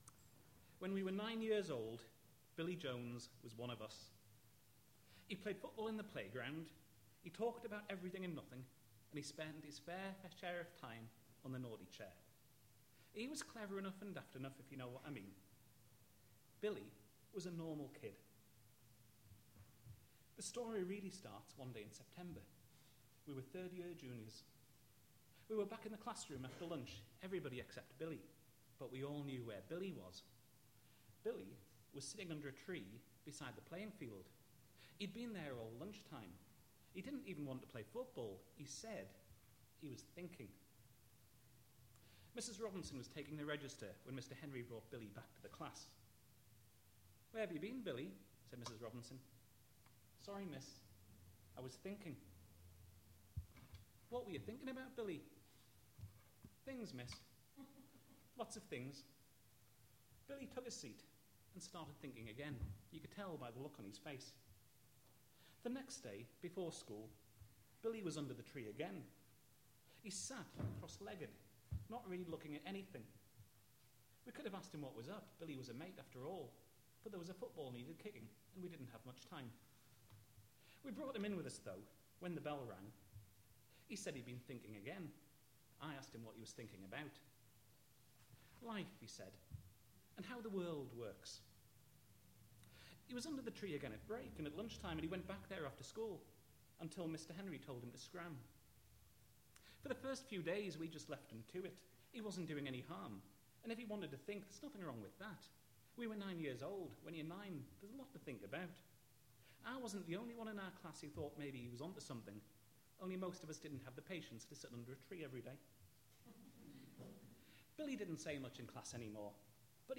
We4Poets Live at the IABF, Manchester